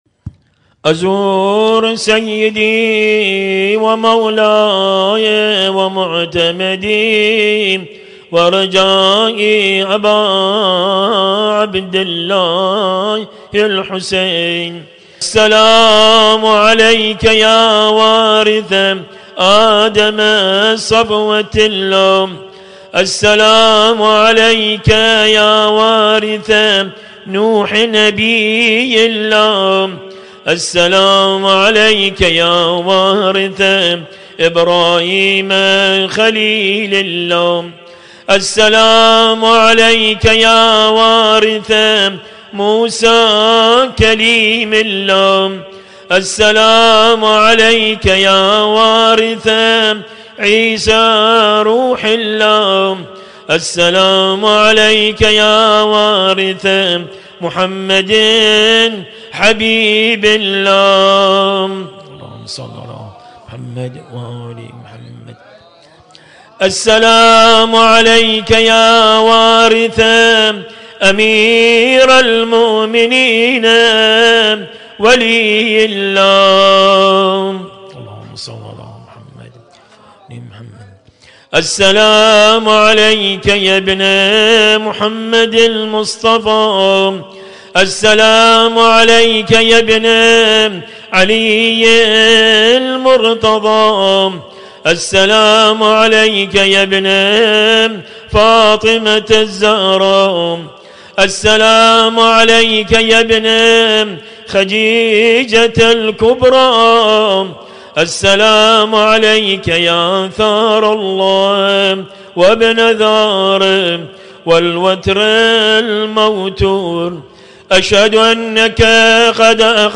القارئ: - الرادود